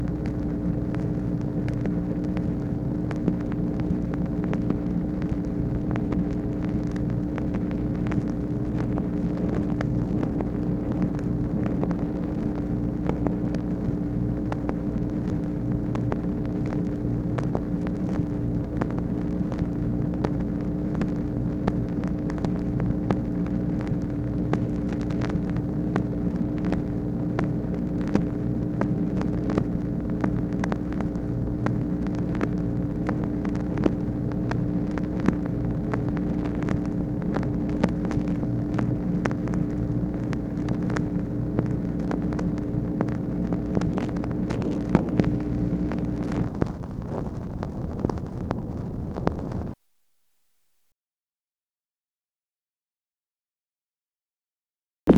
MACHINE NOISE, November 4, 1964
Secret White House Tapes | Lyndon B. Johnson Presidency